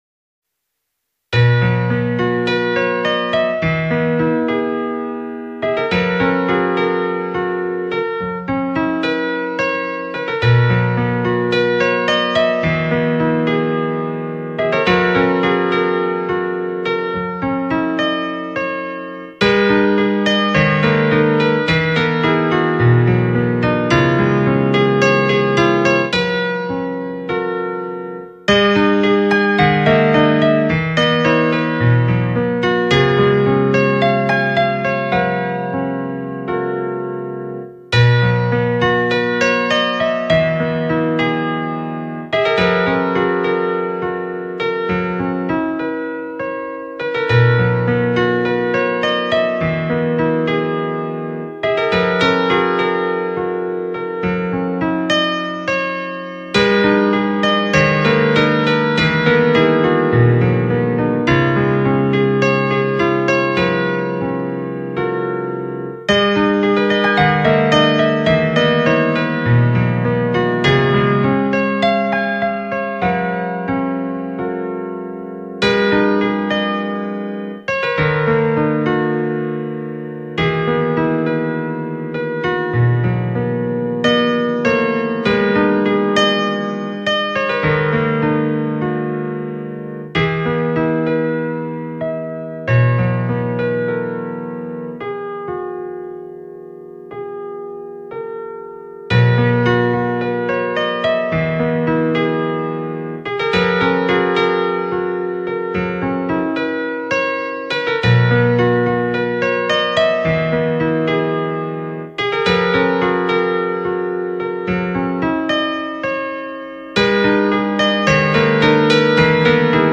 乐器: 钢琴
类型：NEWAGE
风格：POP，SOUL
以精湛的钢琴独奏重新诠释，简单而直接的感动，
他触键的指法流畅，佐以轻盈的装饰音，这是表达喜的标准表情。